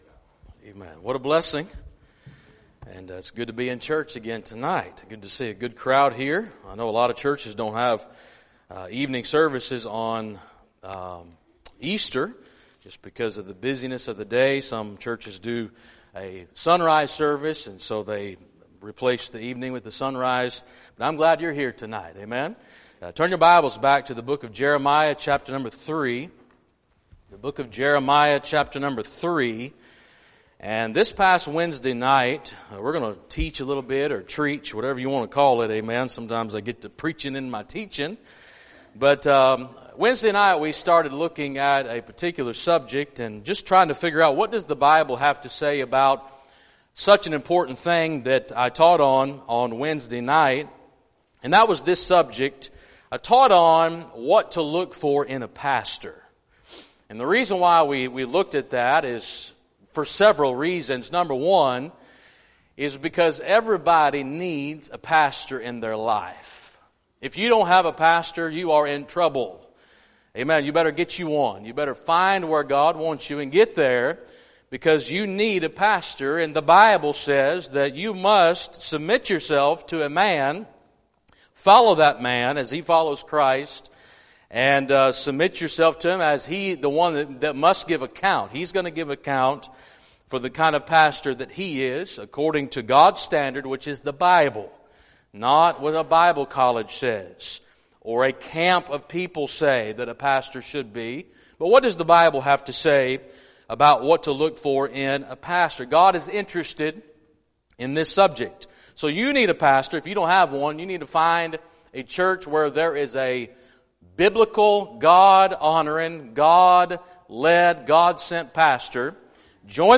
Topic Sermons